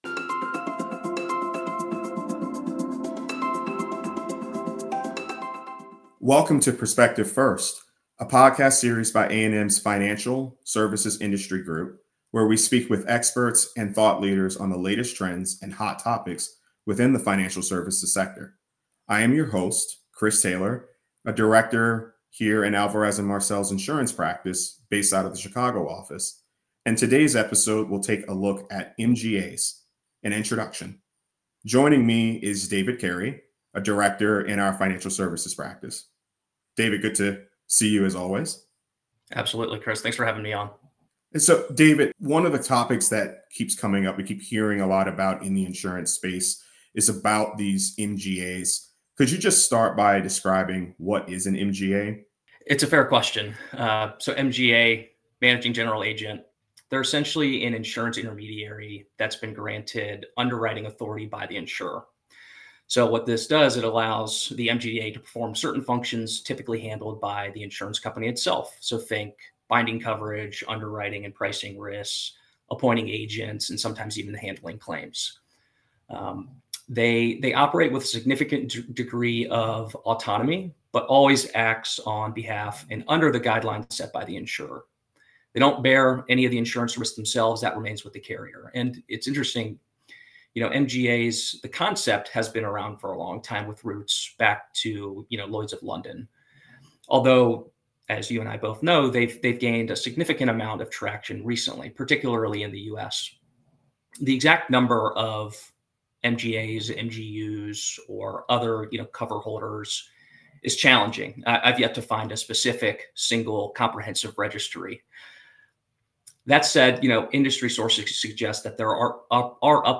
Don't miss this insightful conversation!